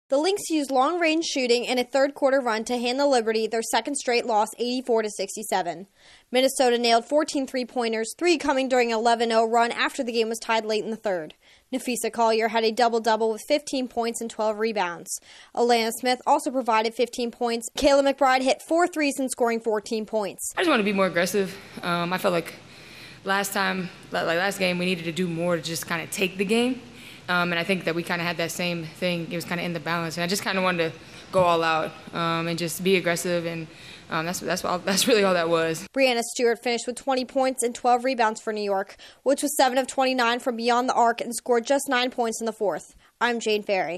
The Lynx take over down the stretch to beat the Liberty. Correspondent